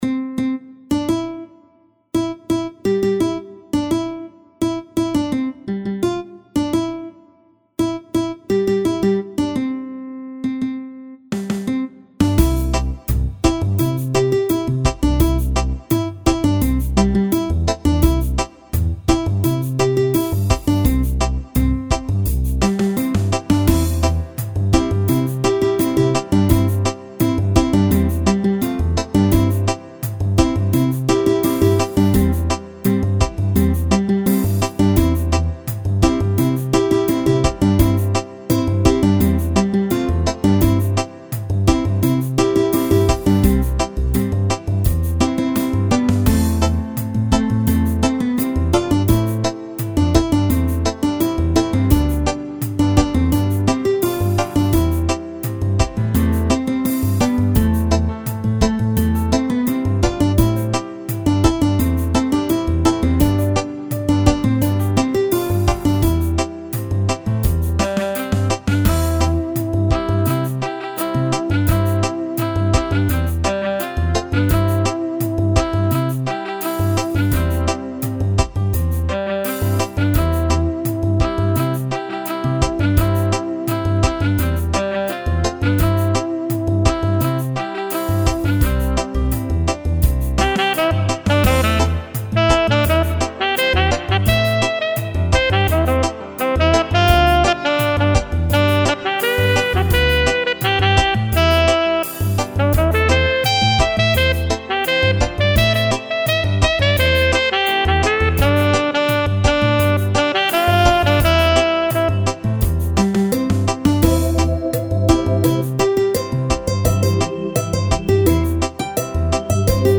Gospel tune in Reggae style.